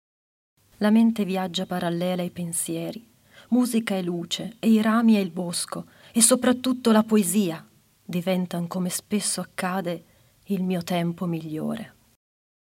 dall’audiolibro Komorebi